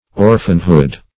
Orphanhood \Or"phan*hood\, n.